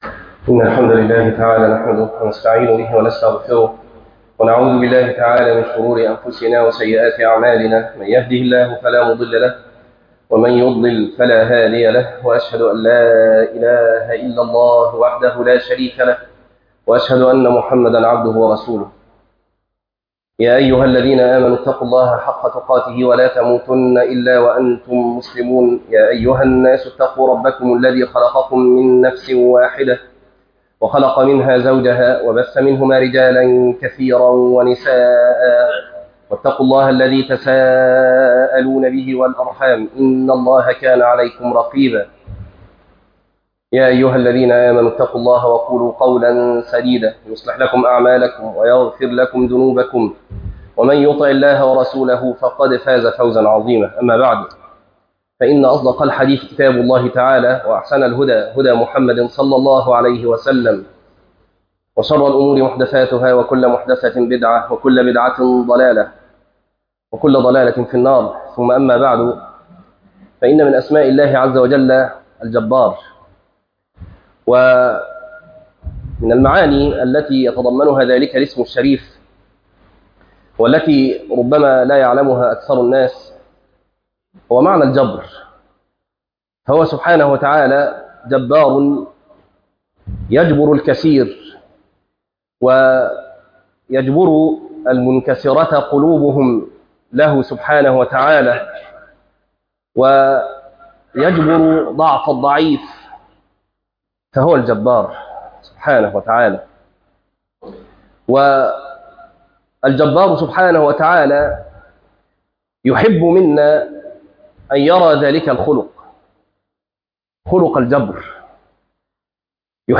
الجبر - خطبة